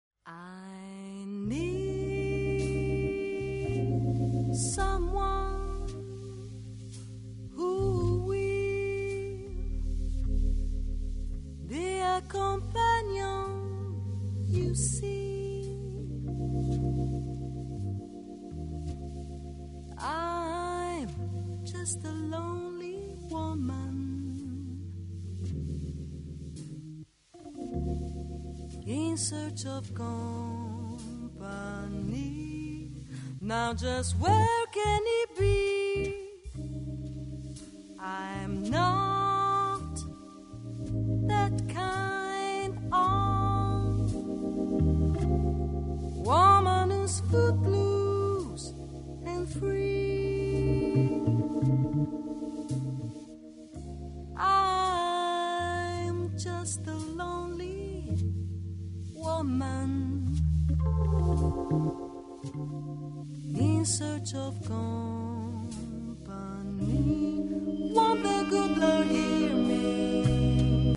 Voce
Batterie
è una jazz ballad